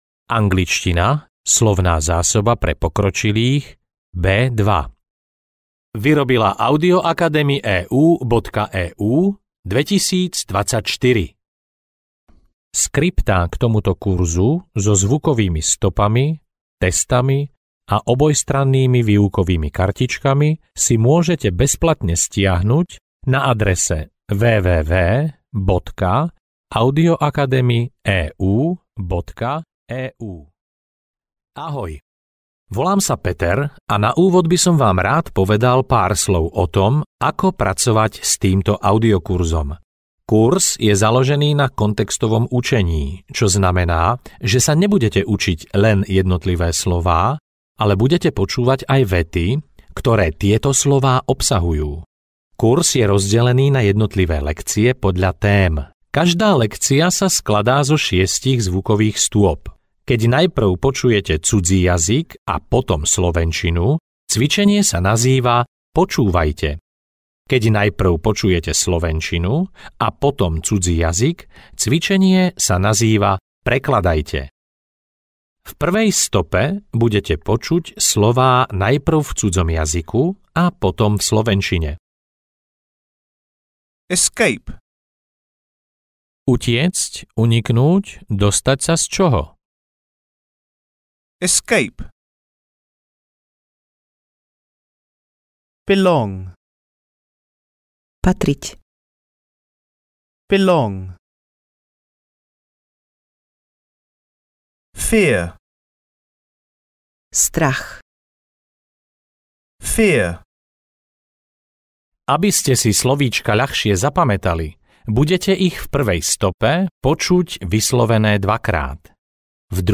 Angličtina pre pokročilých B2 audiokniha
Ukázka z knihy